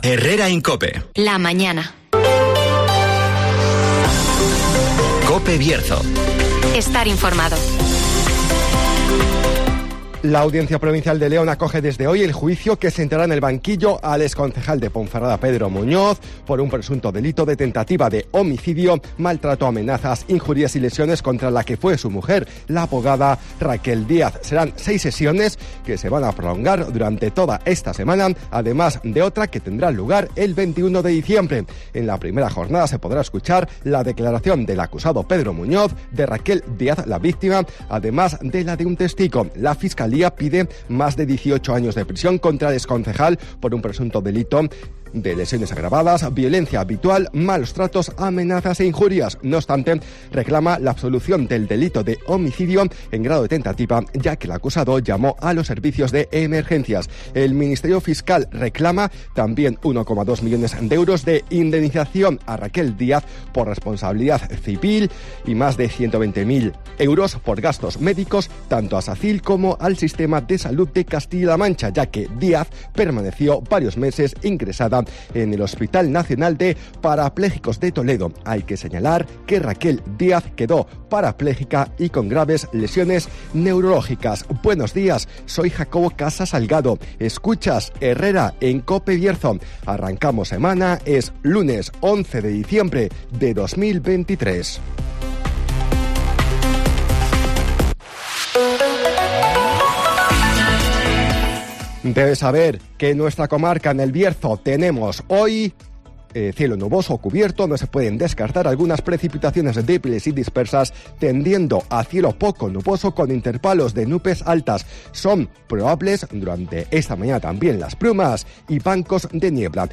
INFORMATIVOS
Repaso a la actualidad informativa del Bierzo. Escucha aquí las noticias de la comarca con las voces de los protagonistas.